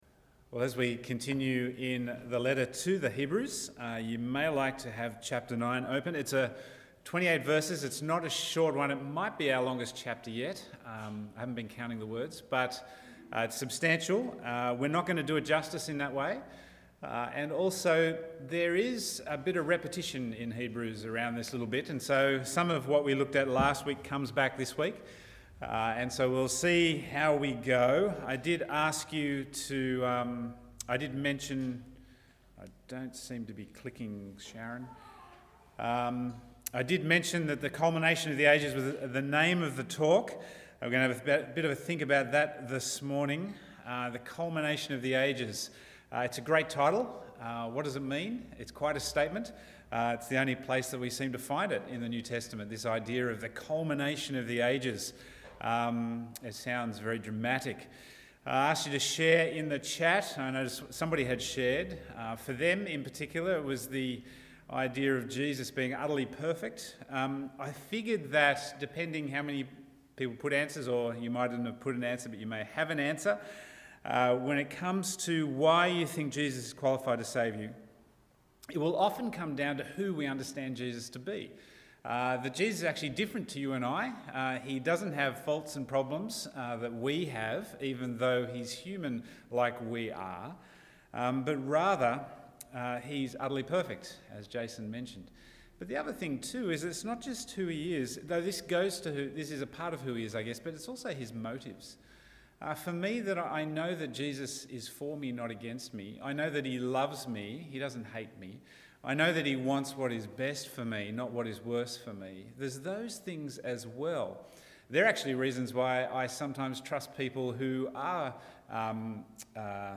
Bible Text: Hebrews 9:1-28 | Preacher